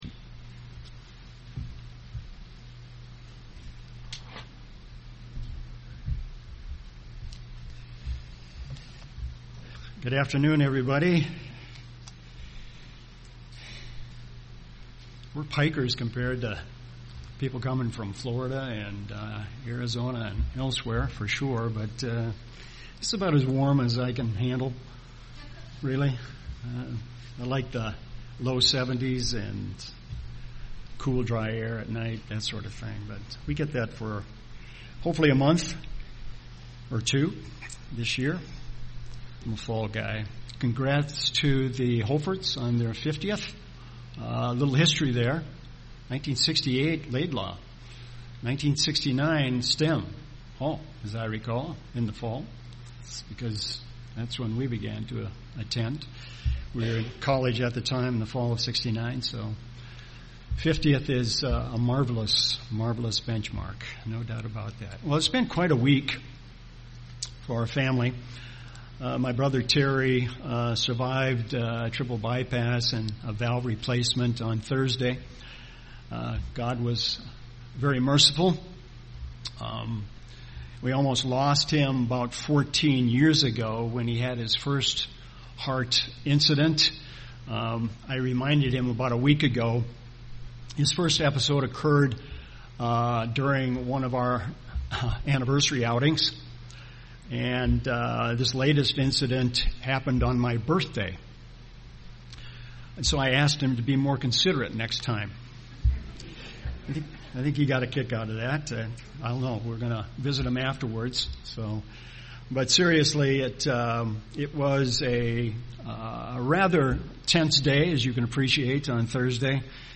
Sermons
Given in Little Falls, MN Twin Cities, MN